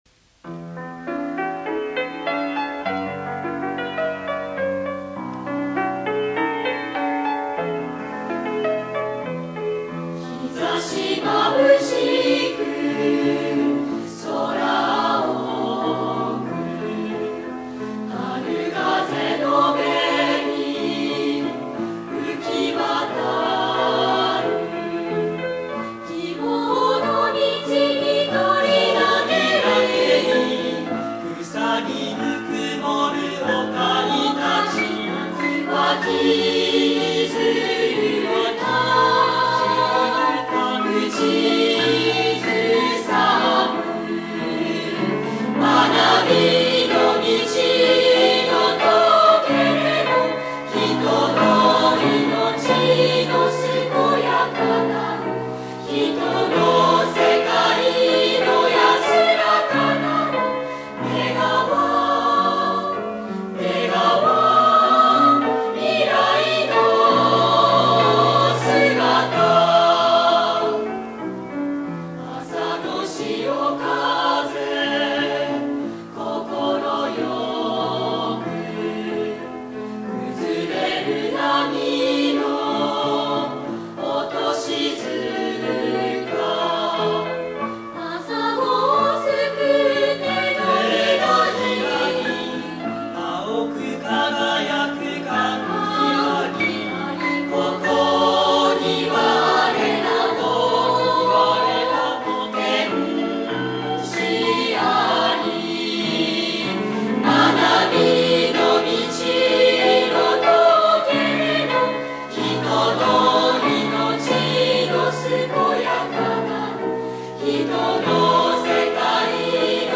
また、詞の趣を最大限生かした森田氏の曲は、旋律がとても美しく、穏やかであり、夢に向って進む学生の「情熱」と「清らかさ」の双方をイメージした曲に仕上がっています。
（演奏：新潟薬科大学合唱団）